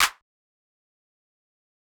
Clap (Mamacita).wav